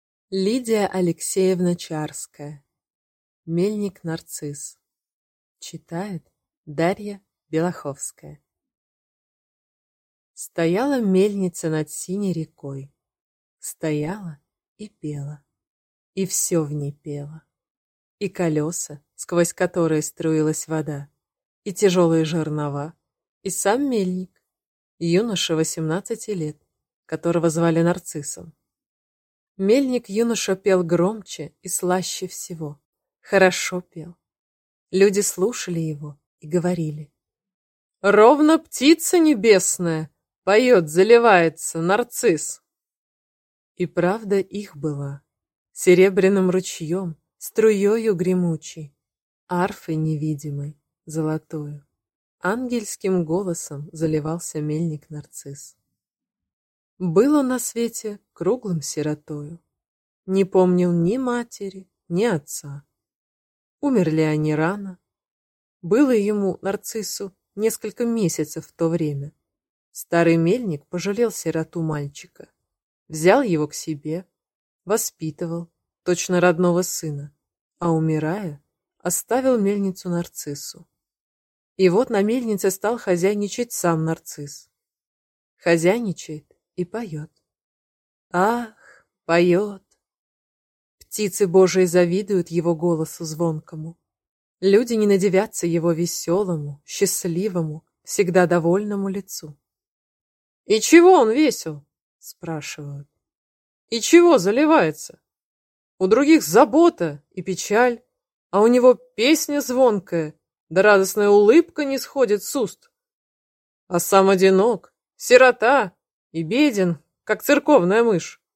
Аудиокнига Мельник Нарцисс | Библиотека аудиокниг